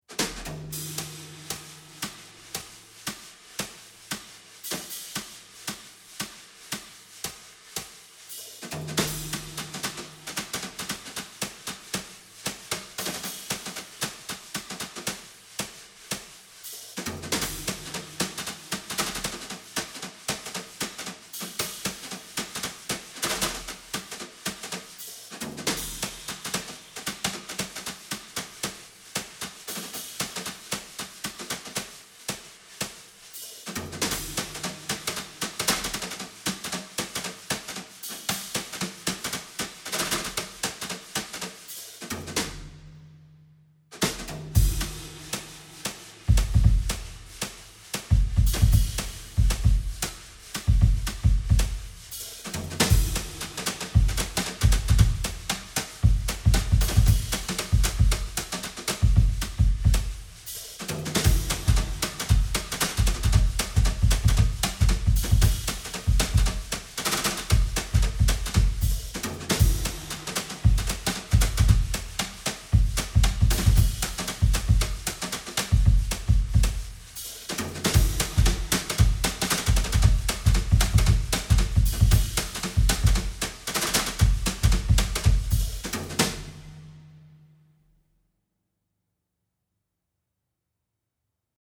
drums only